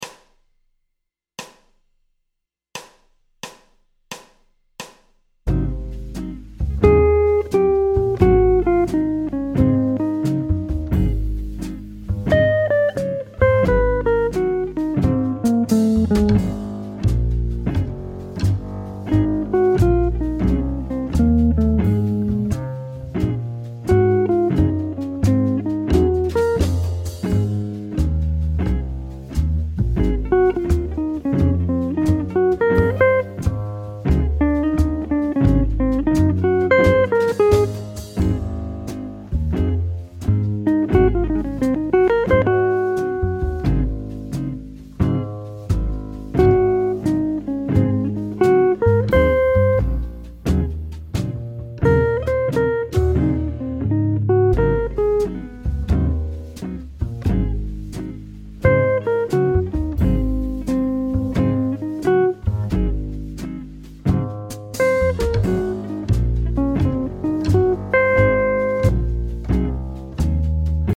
Playback des Licks 305 à 315